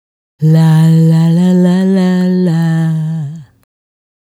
La La La 110-E.wav